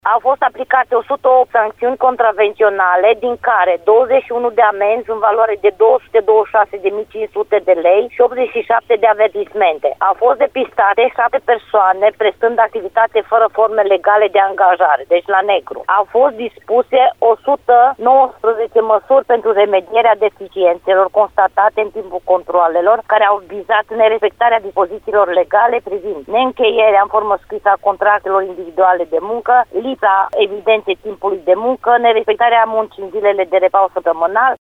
În urma verificărilor, au fost aplicate amenzi în valoare totală de 226.500 de lei, spune șefa Inspectoratului Teritorial de Muncă Timiș, Ileana Mogoșanu.